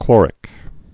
(klôrĭk)